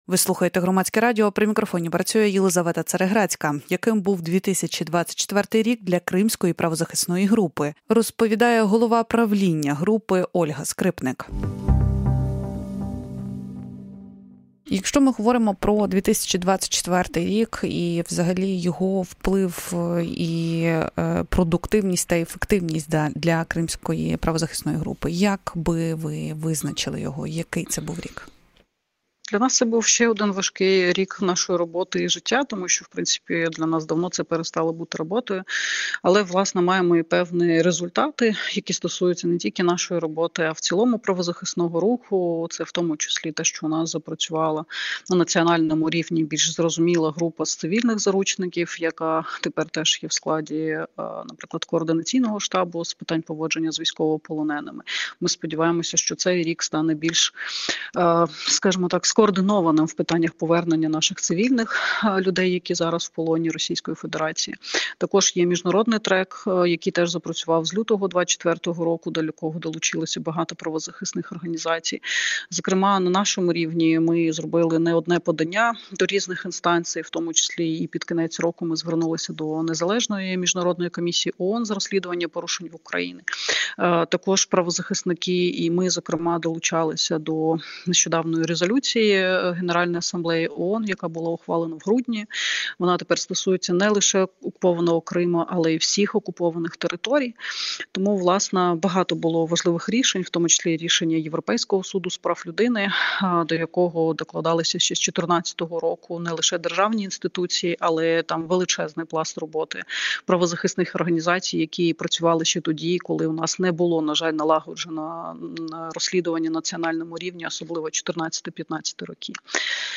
в ефірі Громадського радіо.